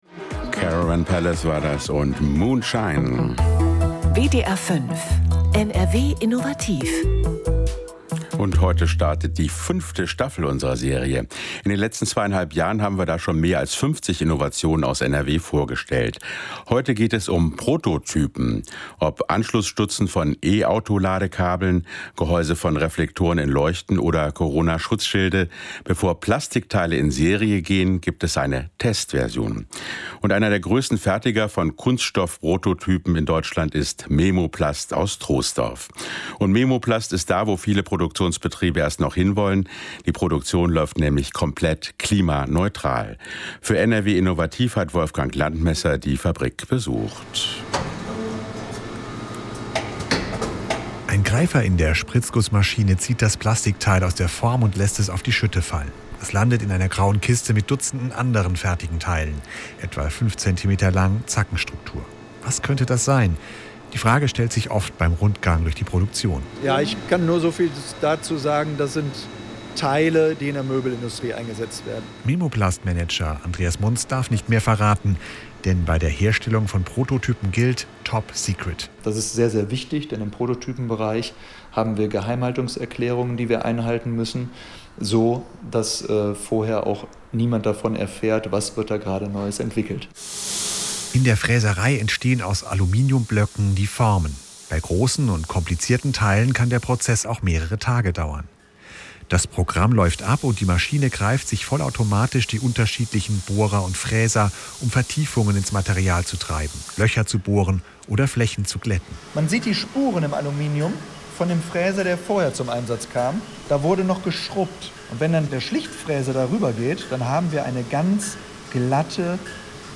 Interview mit NRW Innovativ – WDR 5
Der Beitrag, der im Radio ausgestrahlt wurde, bot uns die Gelegenheit, unsere Expertise im Bereich der Kunststoffspritzguss-Prototypen und Kleinserien zu präsentieren.